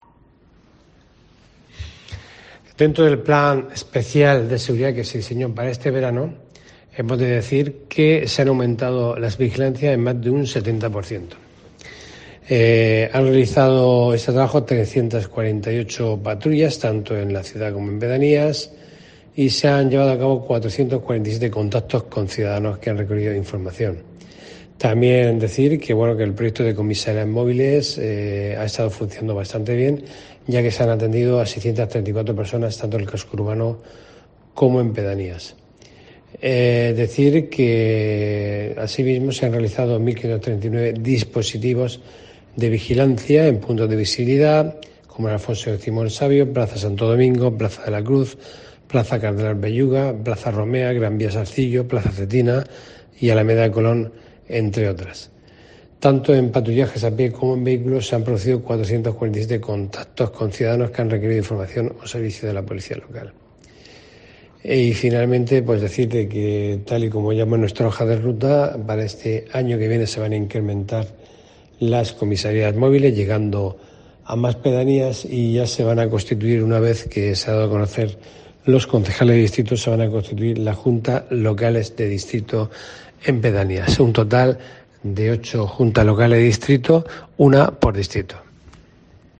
Fulgencio Perona, concejal de Seguridad